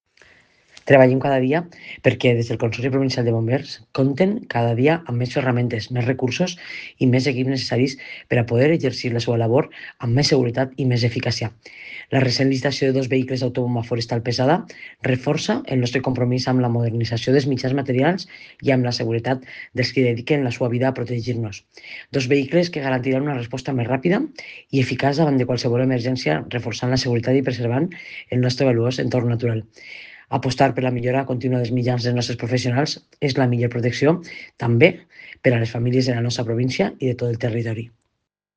Presidenta-Marta-Barrachina-licitacion-vehiculos-bomberos.mp3